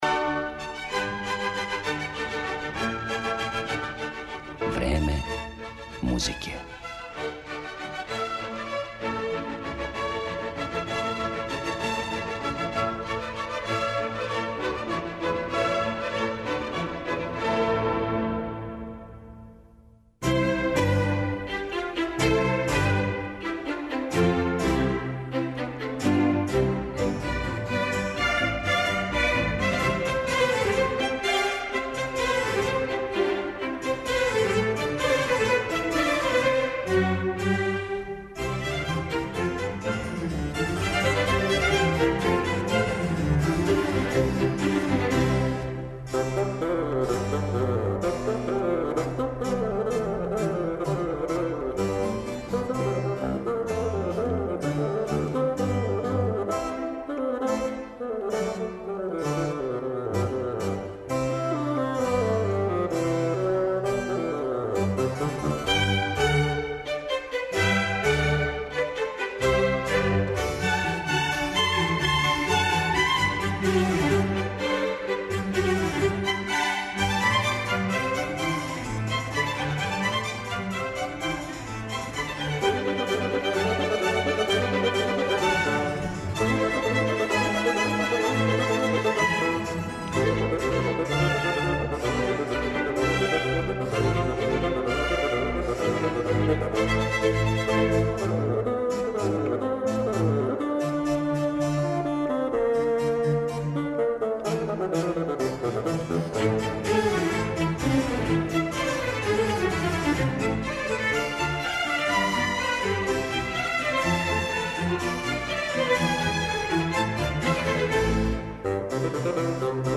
фаготу